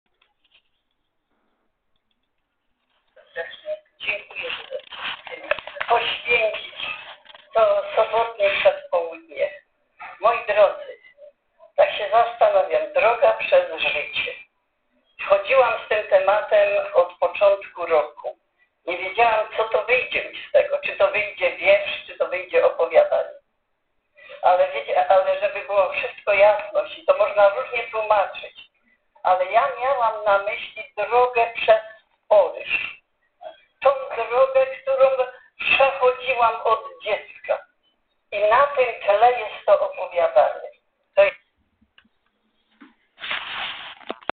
Słuchamy, bijemy brawo etc...